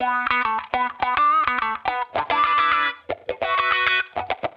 Index of /musicradar/sampled-funk-soul-samples/105bpm/Guitar
SSF_StratGuitarProc2_105B.wav